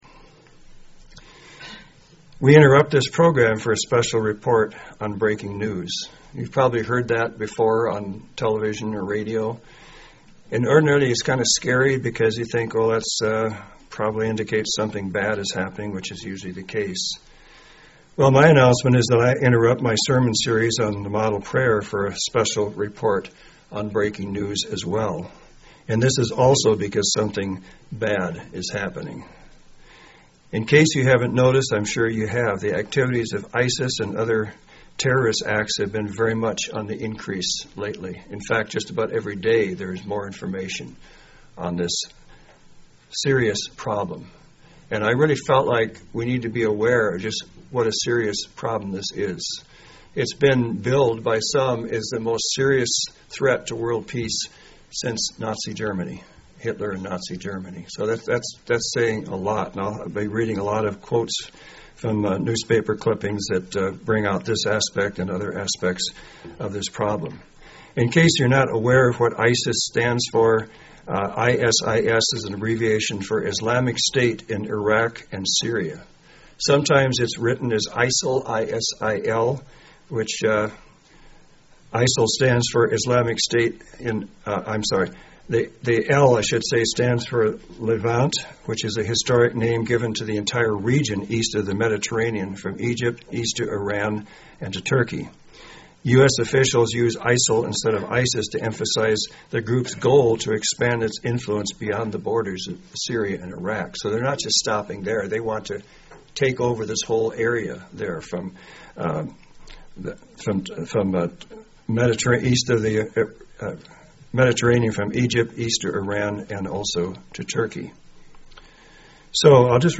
Given in Central Oregon
UCG Sermon Studying the bible?